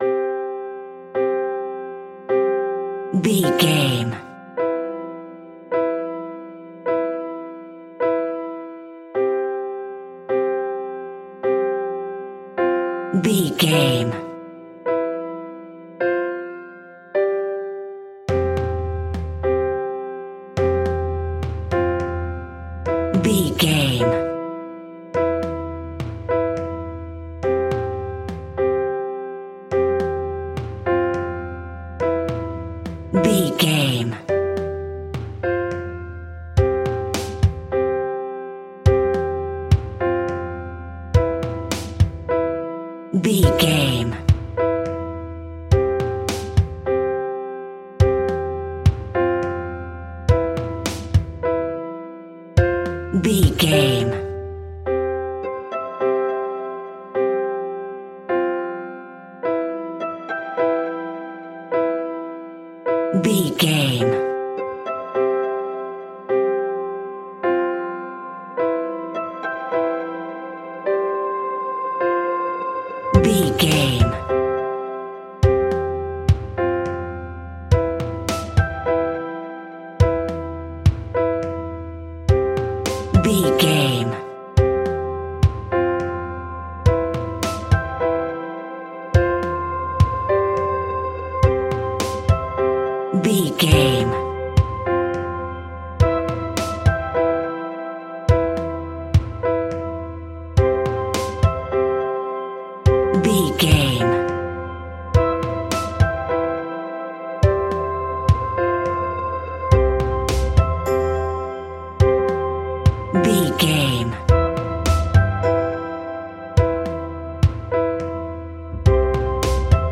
Uplifting
Ionian/Major